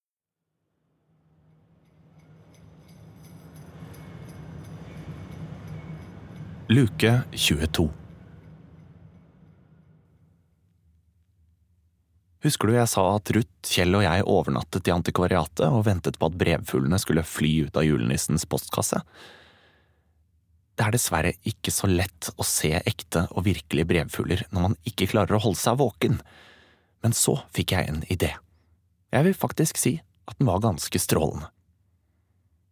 Snøfall 22. desember (lydbok) av Hanne Hagerup